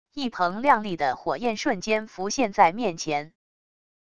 一蓬亮丽的火焰瞬间浮现在面前wav音频生成系统WAV Audio Player